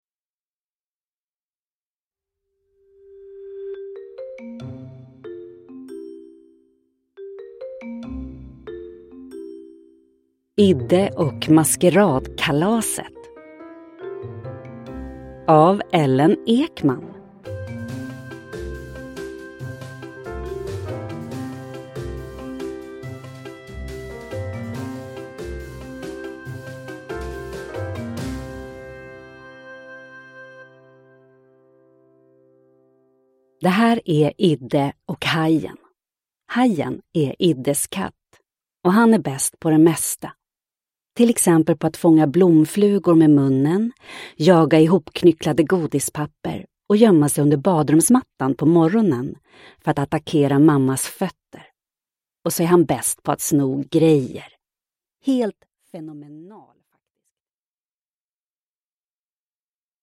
Idde och maskeradkalaset – Ljudbok